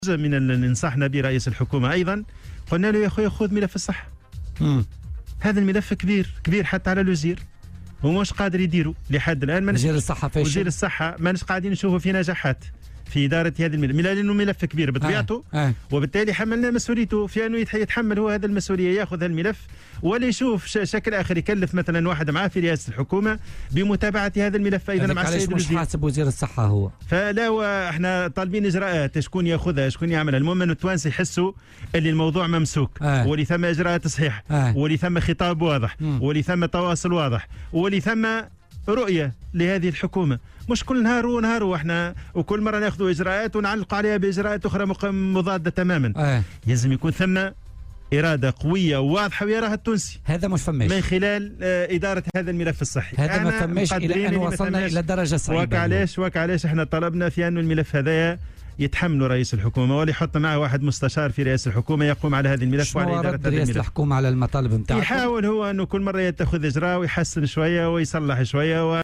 وأوضح في مداخلة له اليوم في برنامج "بوليتيكا" أنهم دعوا رئيس الحكومة الى تكليف مستشار في رئاسة الحكومة لمتابعة هذا الملف.